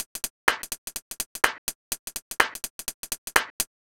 Weird Glitch 01.wav